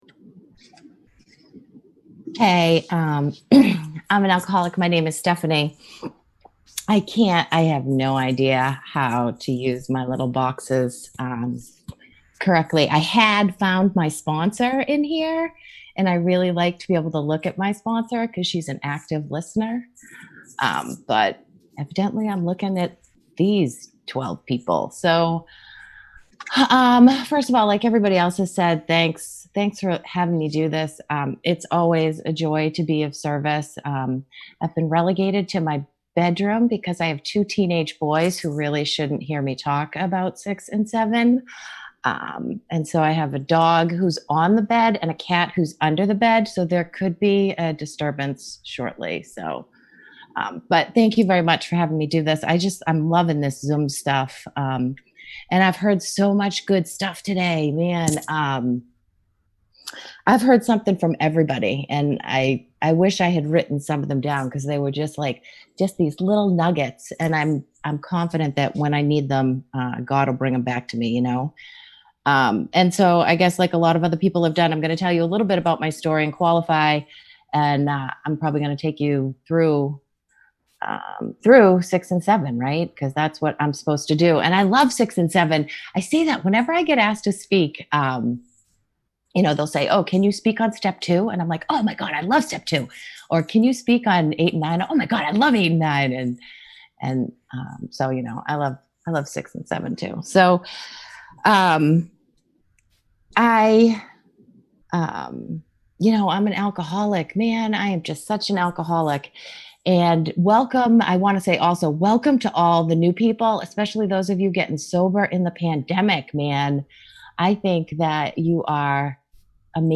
Women's Wisdom AA Weekend